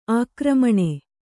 ♪ ākramaṇe